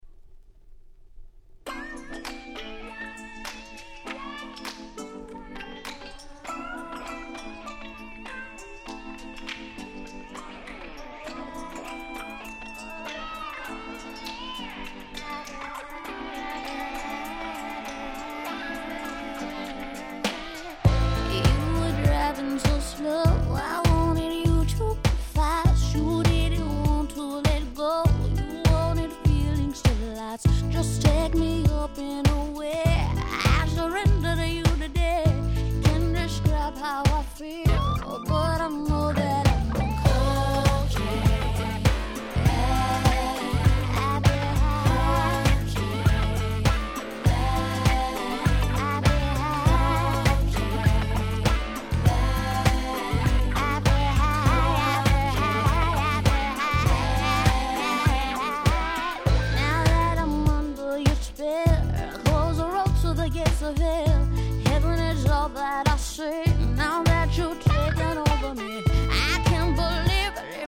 98' Nice R&B LP !!
統一したNeo Soul感が素晴らしい名盤中の名盤です！